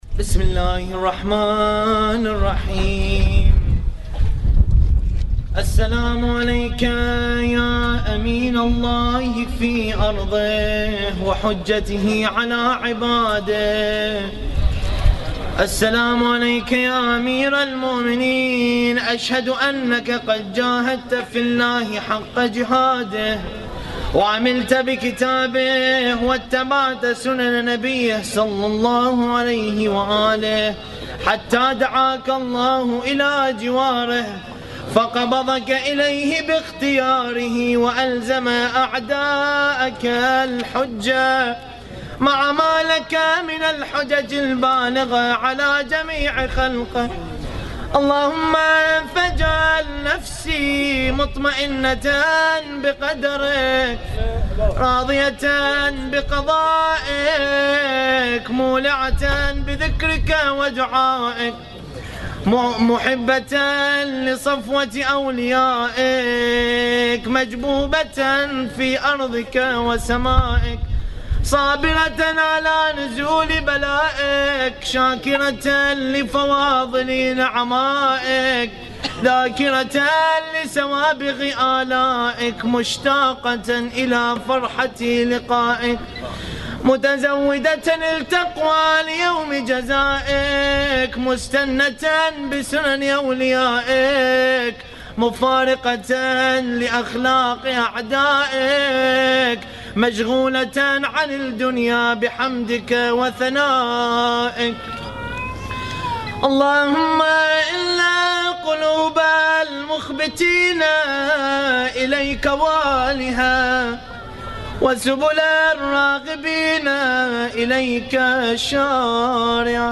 الرادود